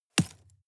Gemafreie Sounds: Steine